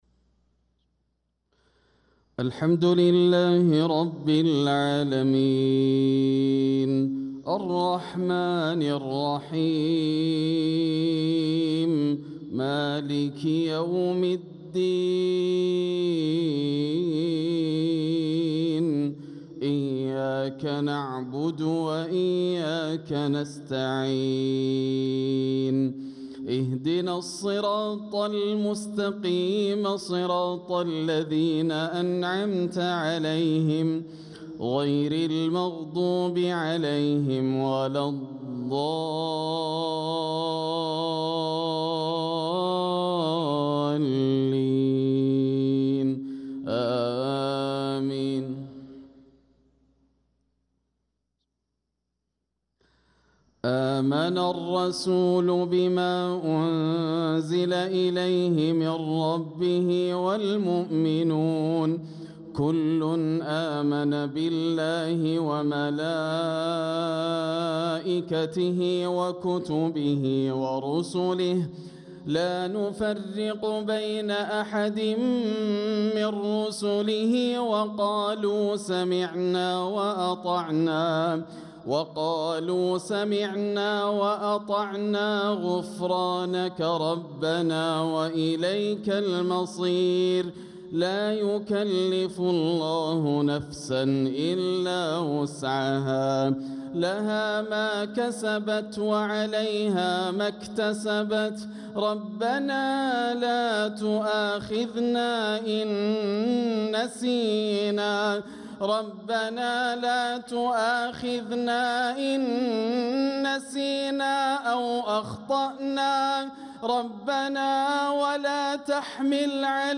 صلاة المغرب للقارئ ياسر الدوسري 18 ربيع الآخر 1446 هـ
تِلَاوَات الْحَرَمَيْن .